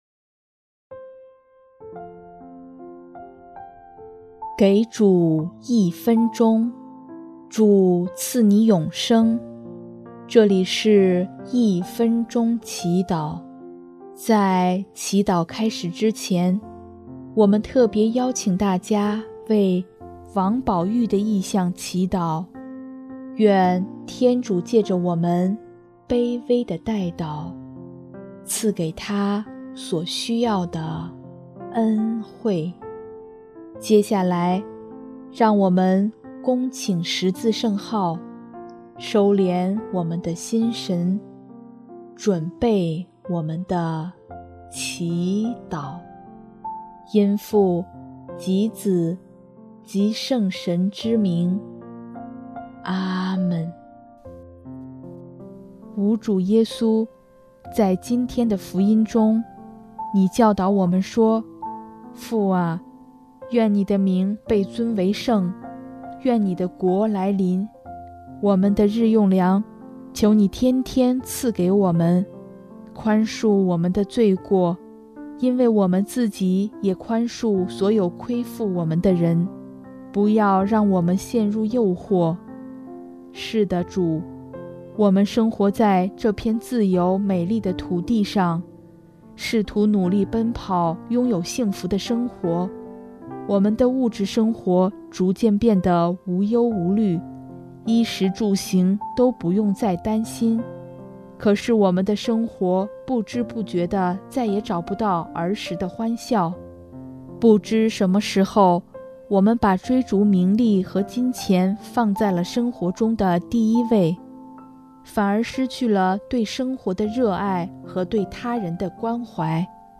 音乐： 第三届华语圣歌大赛参赛歌曲《阿爸天父》